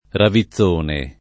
ravizzone [ ravi ZZ1 ne ]